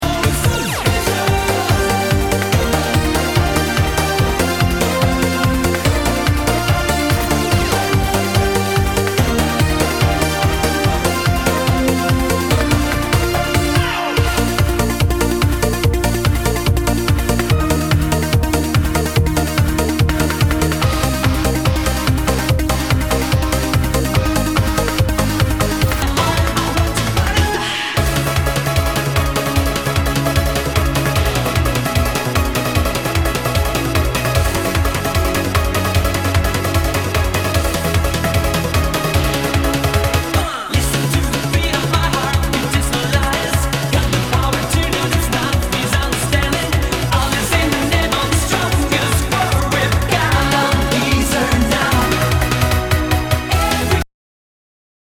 HOUSE/TECHNO/ELECTRO
ナイス！ユーロビート！